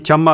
[cama]